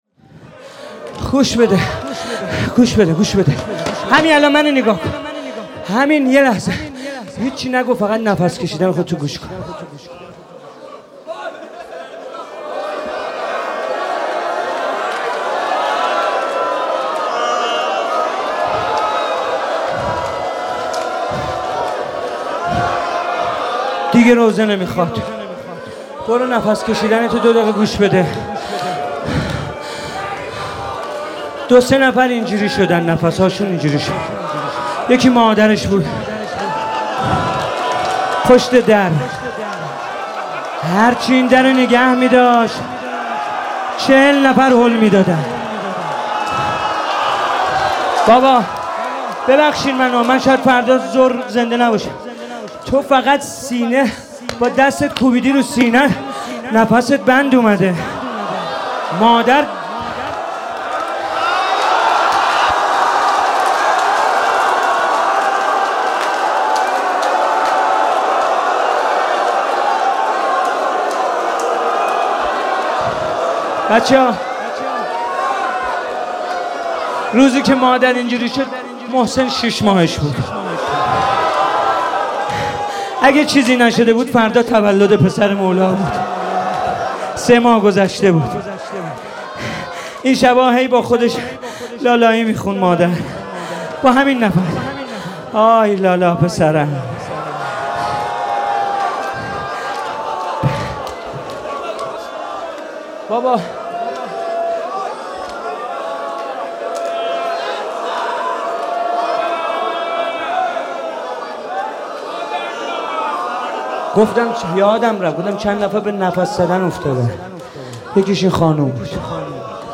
روضه حضرت زهرا سلام‌الله‌علیها – محتوانشر
مداحی_شهادت حضرت زهرا